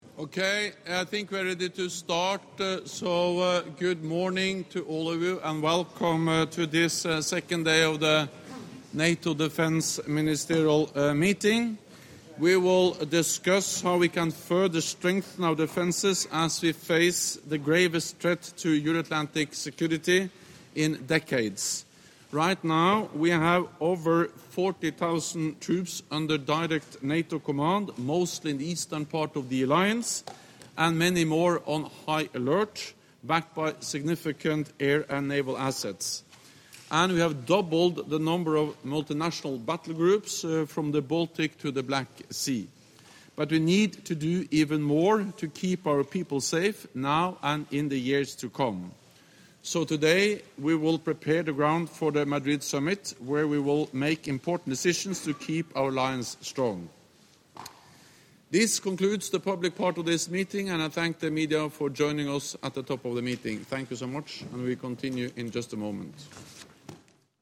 ORIGINAL - Press conference by NATO Secretary General Jens Stoltenberg following the meetings of NATO Defence Ministers